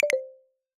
moveerror.wav